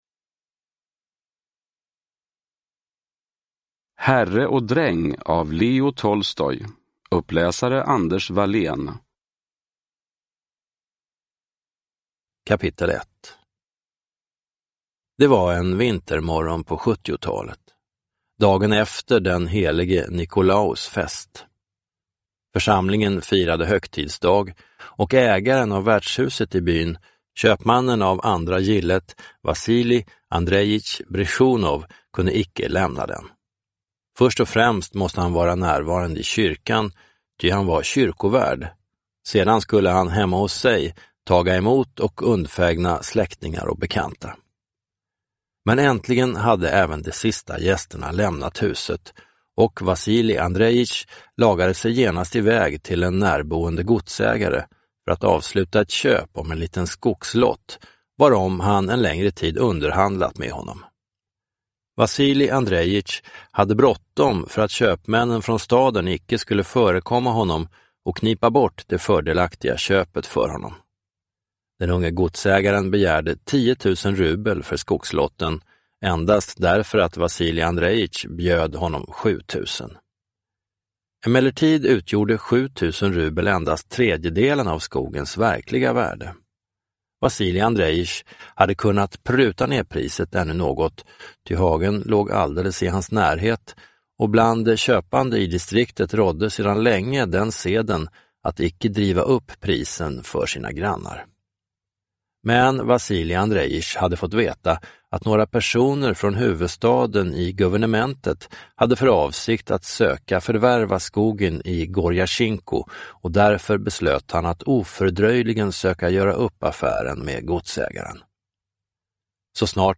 Herre och Dräng – Ljudbok – Laddas ner